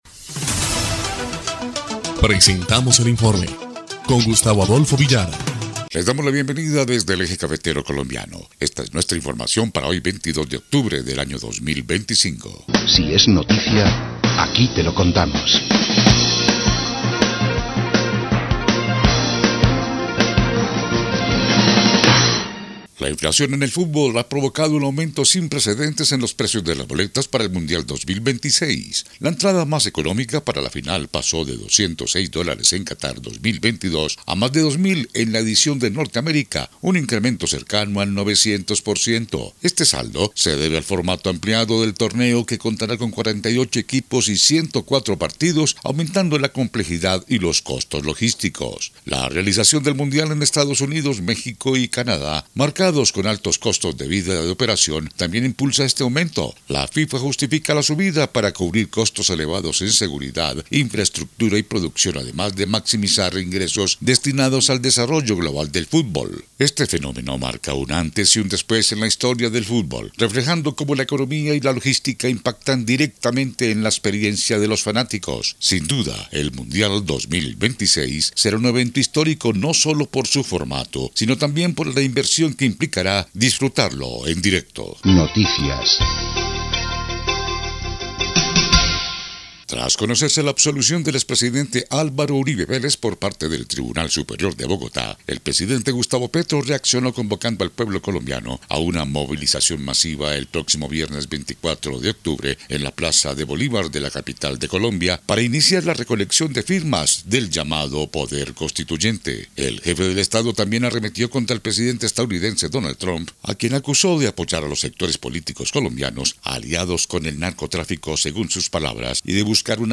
EL INFORME 1° Clip de Noticias del 22 de octubre de 2025